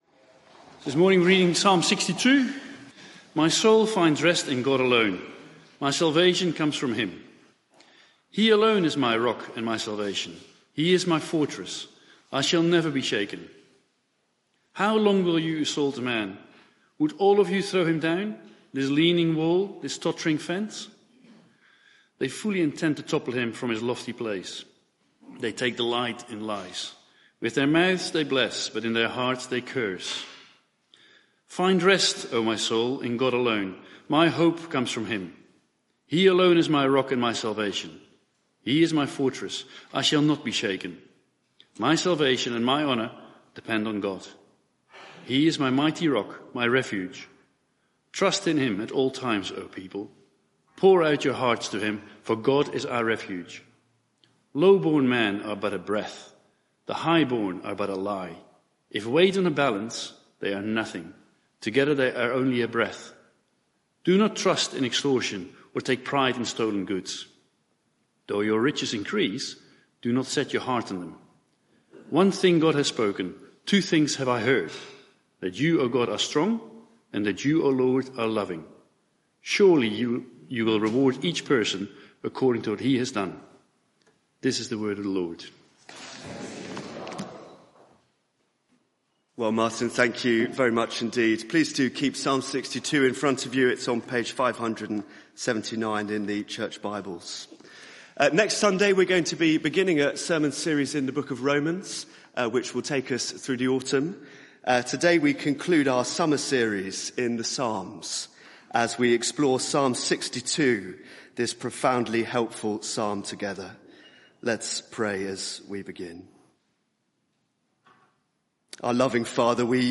Media for 9:15am Service on Sun 03rd Sep 2023 09:15 Speaker
Lord Teach us to Pray Theme: Psalm 62 Sermon (audio)